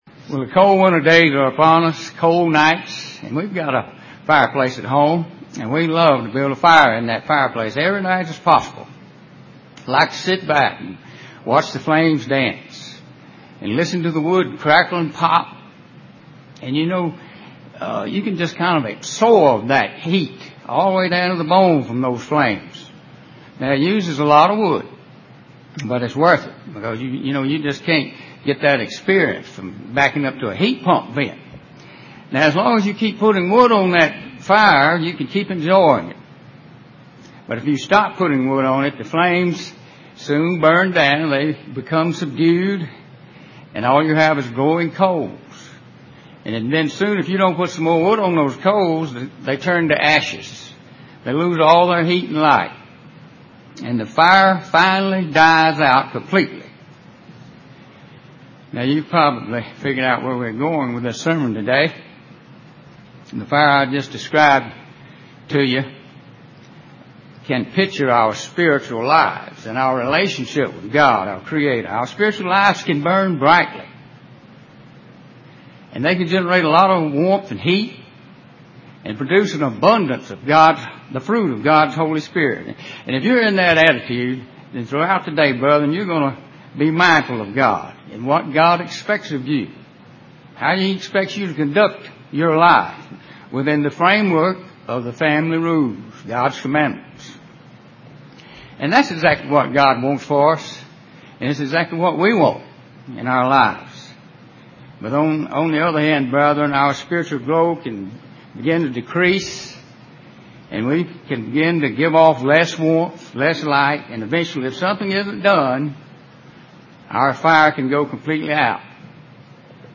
UCG Sermon Studying the bible?
Given in Columbus, GA Central Georgia